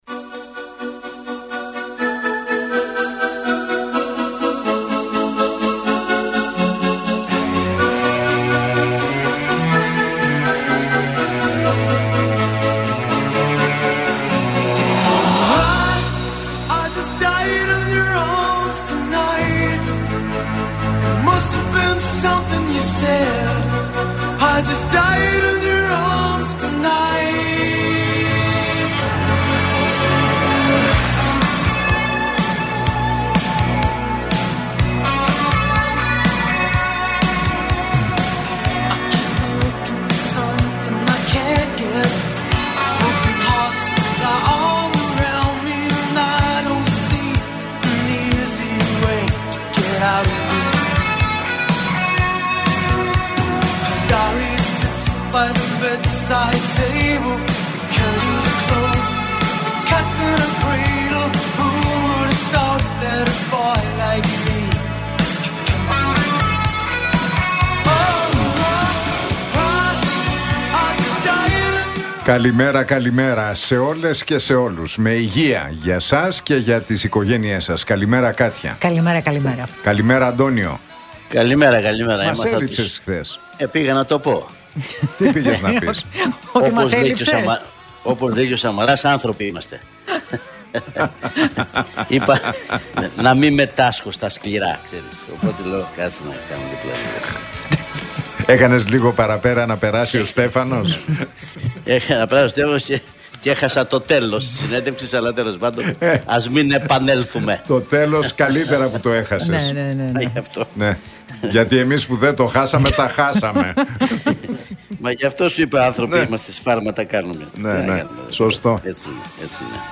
Ακούστε την εκπομπή του Νίκου Χατζηνικολάου στον ραδιοφωνικό σταθμό RealFm 97,8, την Τρίτη 25 Φεβρουαρίου 2025.